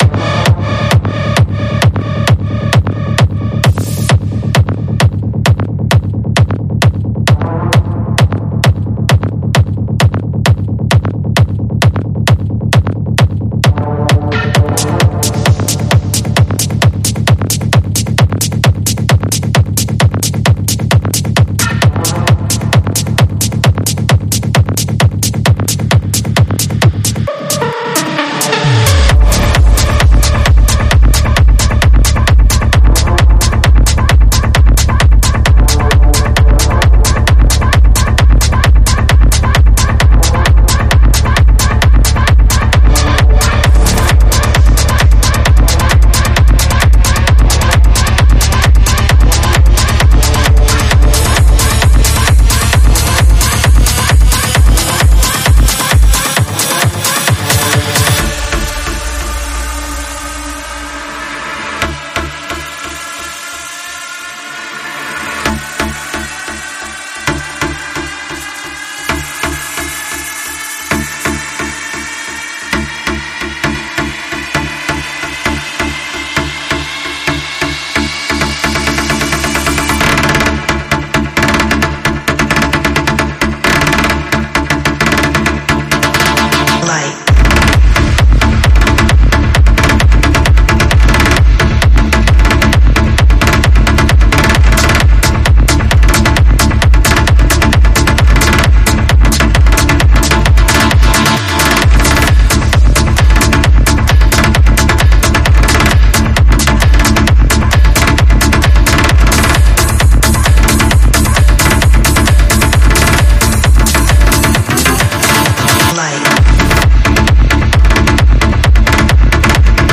试听文件为低音质，下载后为无水印高音质文件 M币 8 超级会员 免费 购买下载 您当前未登录！